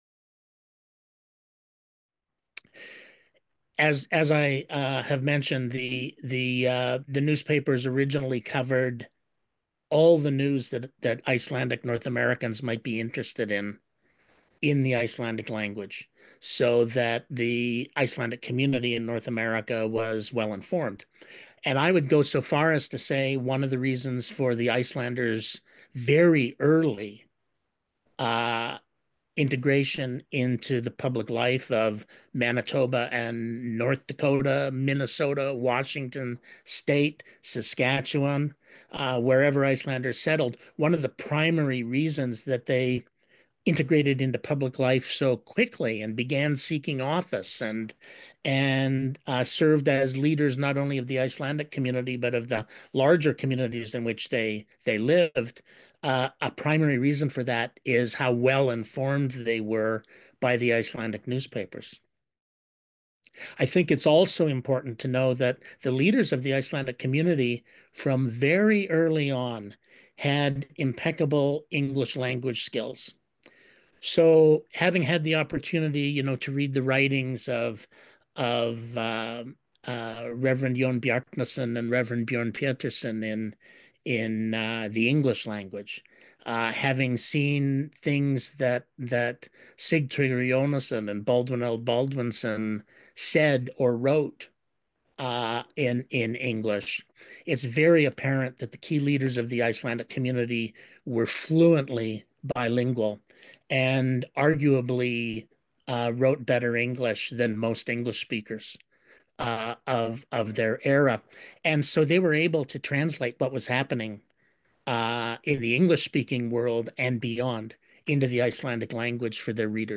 SJ-interview-clip-3.mp3